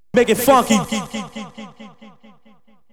VOX SHORTS-2 0005.wav